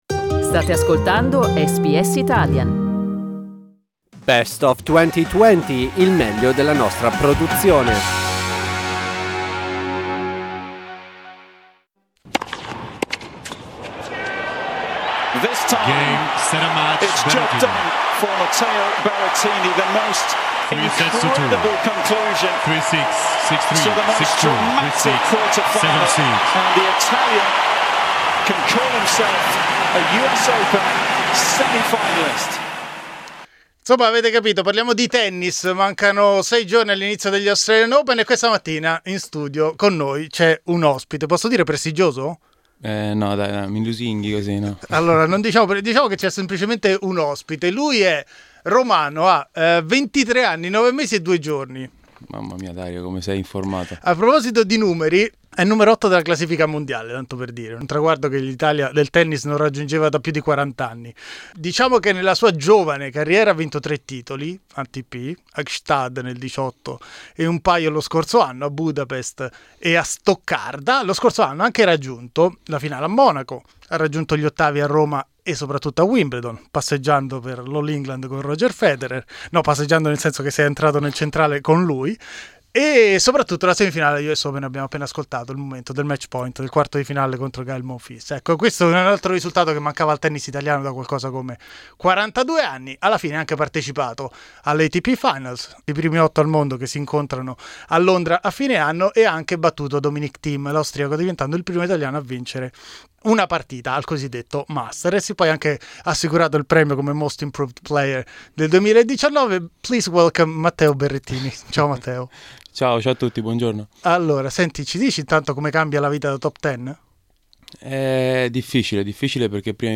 Il numero 1 del tennis italiano ci è venuto a trovare nei nostri studi alla vigilia degli Australian Open 2020 e si è raccontato a cuore aperto.